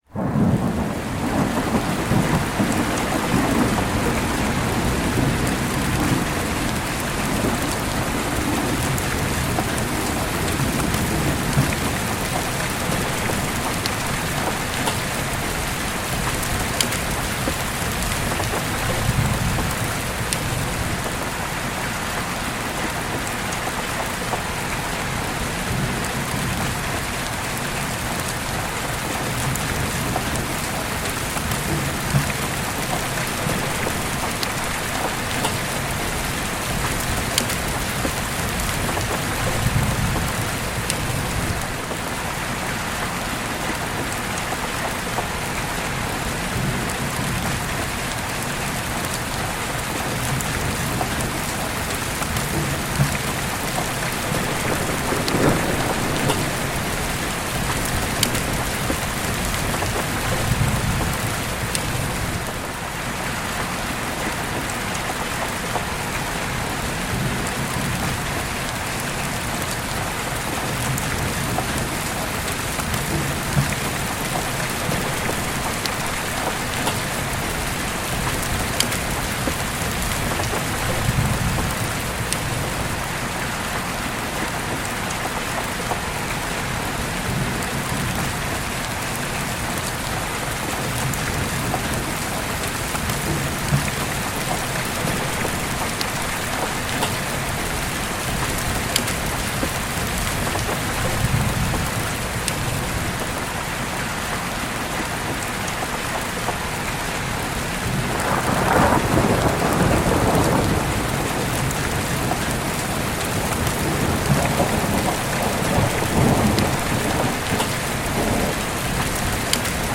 Thunderstorm Cabin Sleep Nature Relax Study